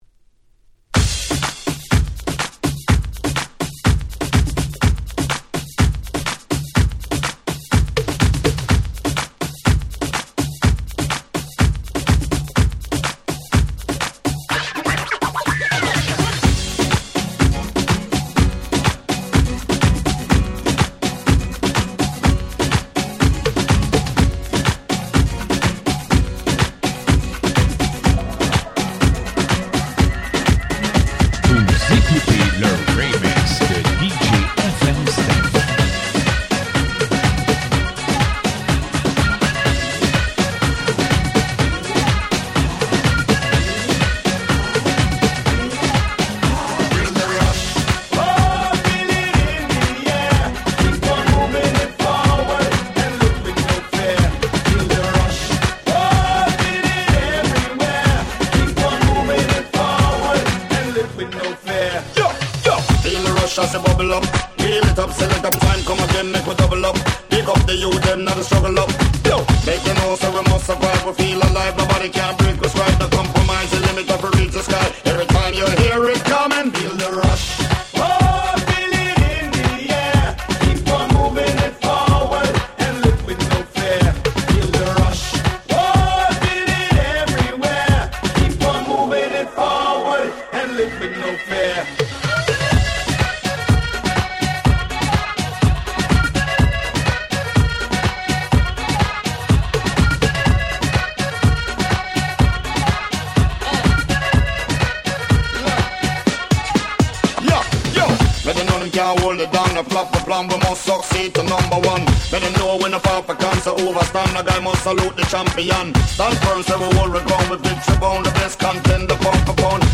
White Press Only Remix !!
どのRemixもアゲアゲ仕様で非常に使えます！
レゲエ